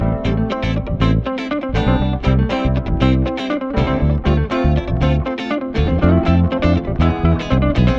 31 Backing No Brass PT2.wav